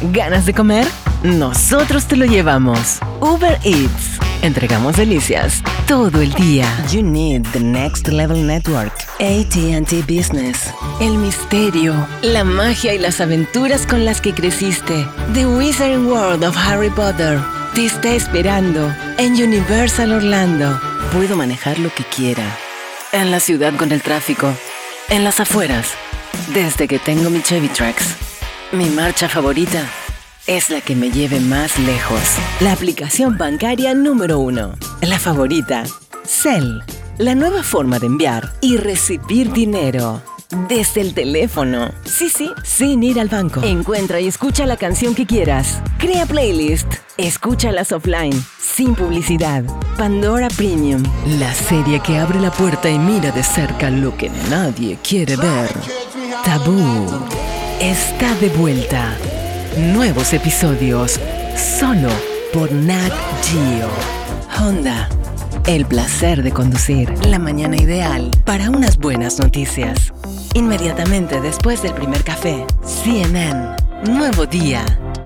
DEMO COMERCIAL LATAM 2022
El clásico mix de spots publicitarios de la temporada, resumiendo lo que fue un año repleto de nuevos desafíos.